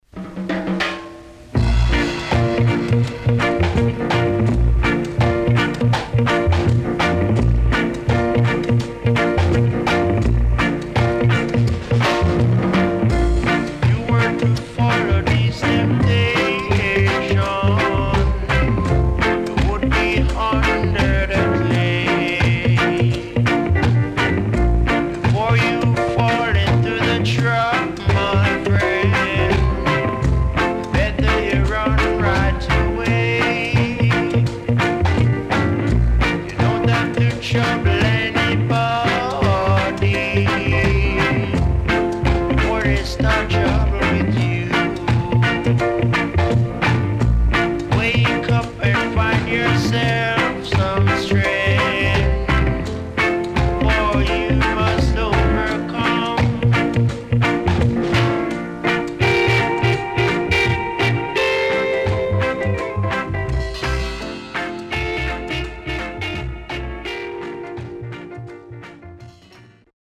HOME > REISSUE [SKA / ROCKSTEADY]